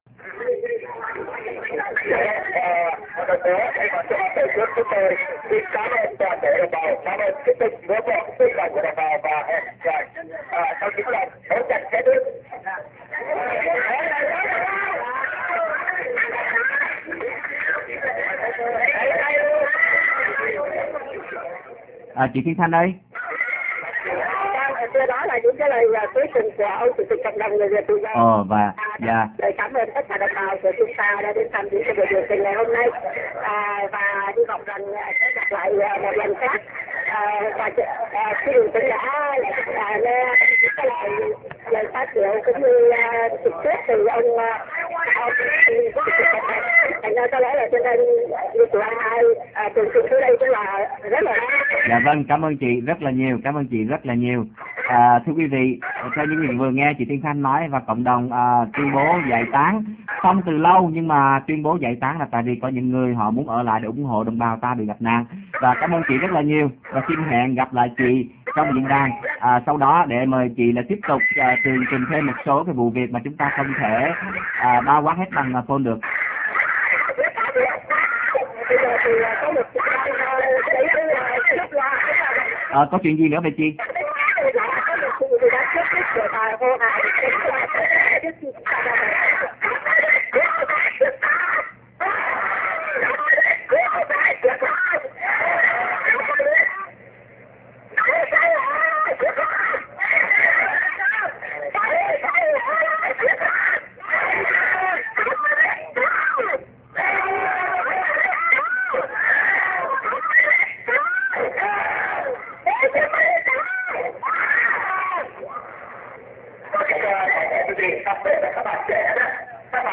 Xin qu� vị chờ trong gi�y l�t sẽ nghe được �m thanh từ cuộc biểu t�nh (phần 2)